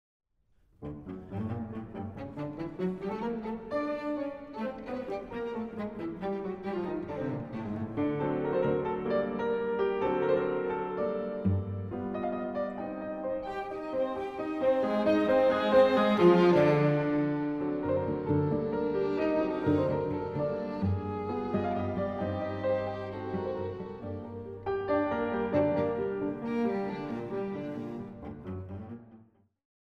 Piano Trio in G minor